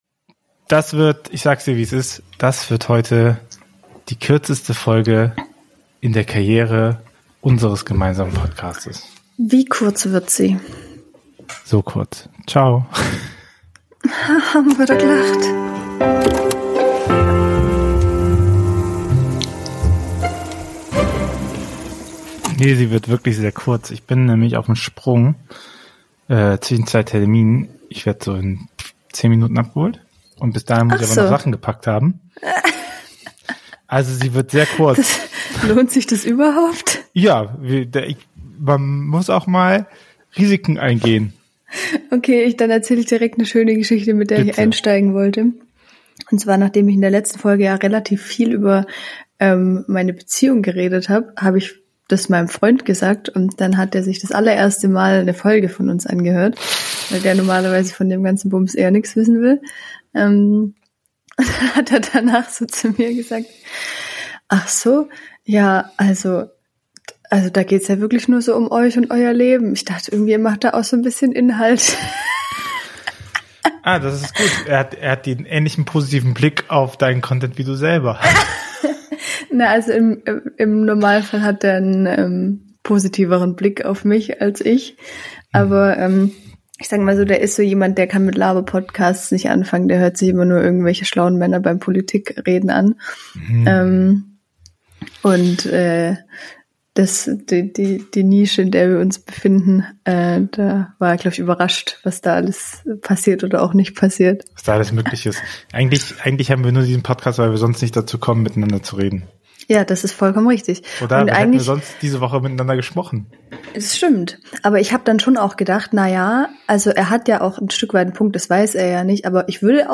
Kurz, knackig, kurz vor der Buchmesse. Einen kleinen, feinen Abstecher in die Podcast Booth